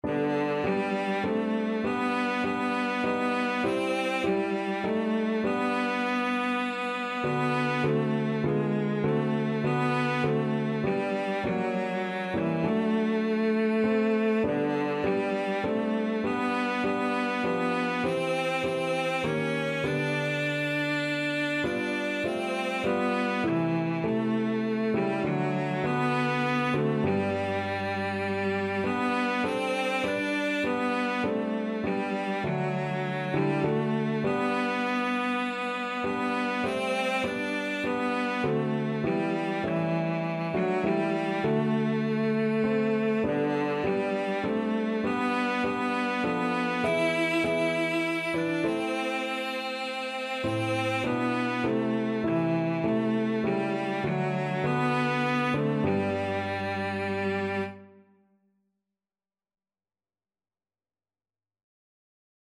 Classical Trad. Moment by Moment (Whittle) Cello version
G major (Sounding Pitch) (View more G major Music for Cello )
3/4 (View more 3/4 Music)
Classical (View more Classical Cello Music)